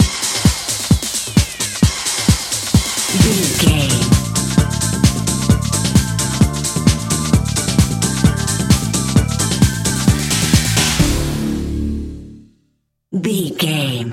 Aeolian/Minor
DOES THIS CLIP CONTAINS LYRICS OR HUMAN VOICE?
WHAT’S THE TEMPO OF THE CLIP?
synthesiser
drum machine
Eurodance